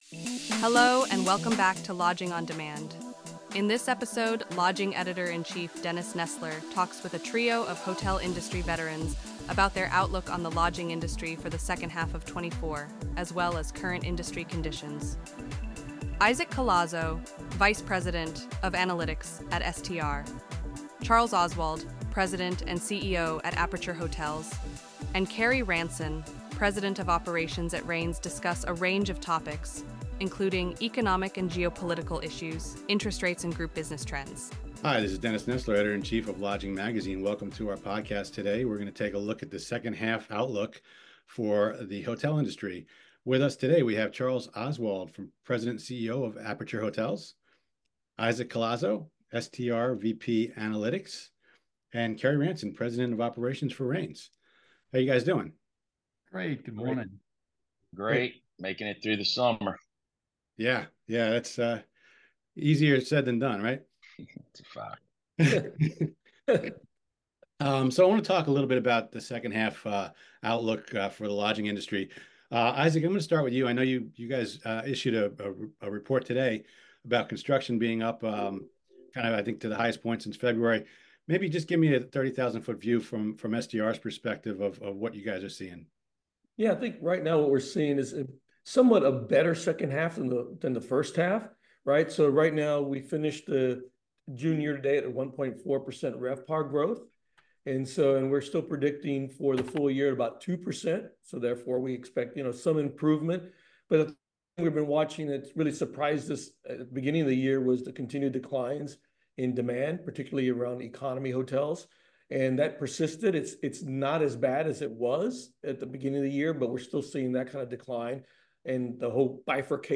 A trio of hotel industry veterans discuss their 2024 second half outlook as well as current industry conditions